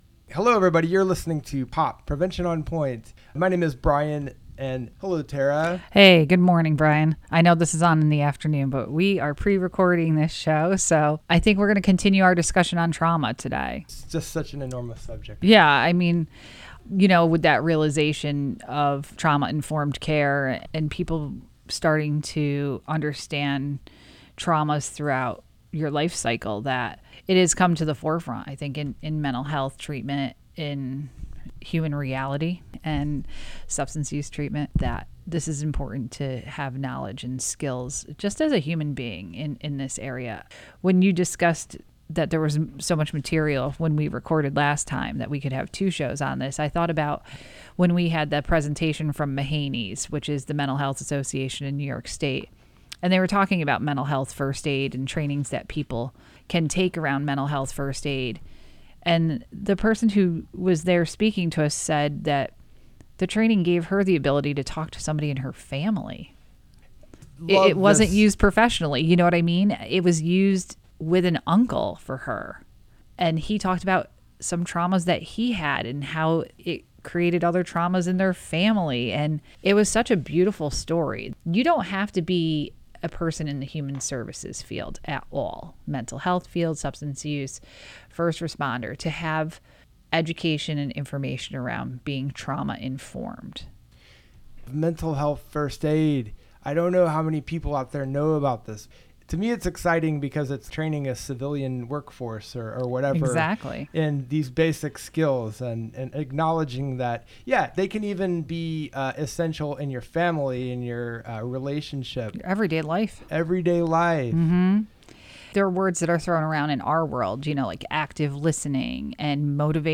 Prevention on Point is devoted to real conversation and useful information about substance use and misuse, prevention, wellness, and community.